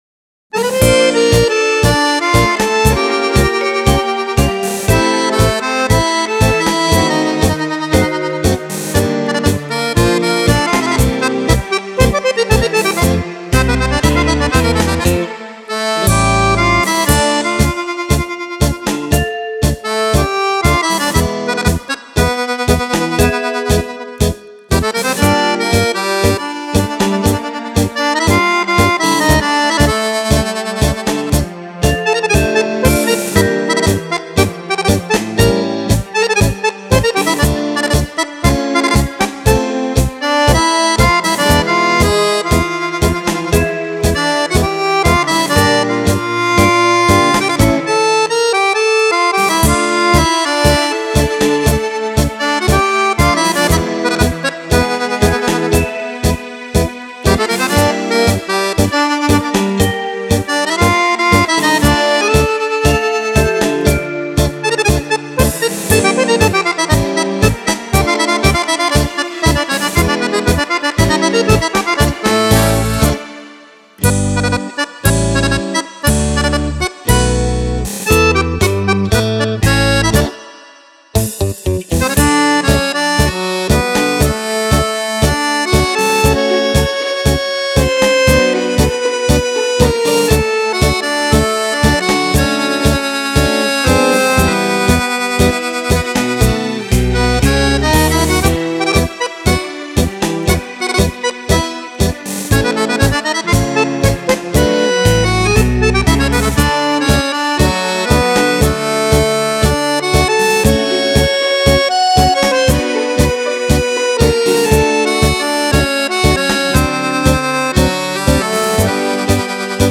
10 ballabili per Fisarmonica
Tango